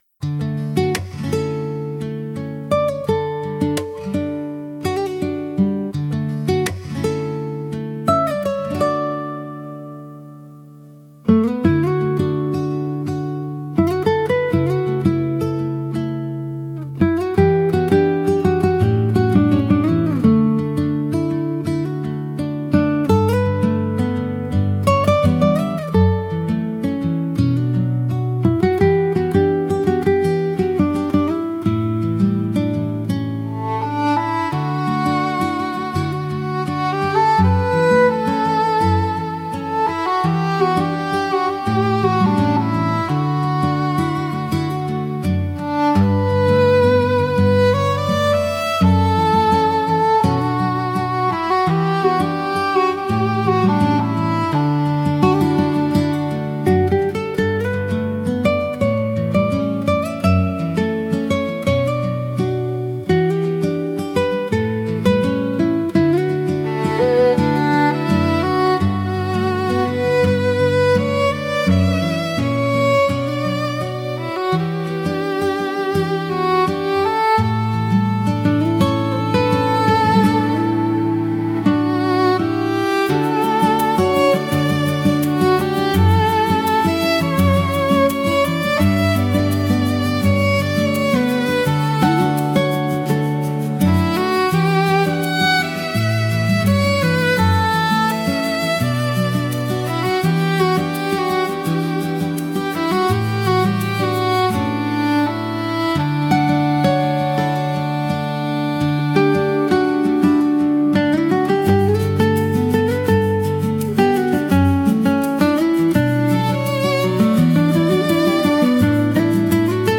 聴く人に安心感と癒しを与え、静かな集中や心の安らぎをサポートしながら、邪魔にならない背景音楽として活用されます。